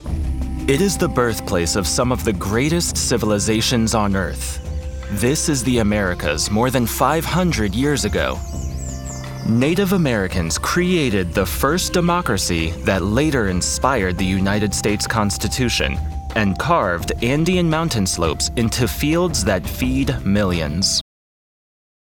Inglés (Estados Unidos)
Documentales
Adulto joven
Mediana edad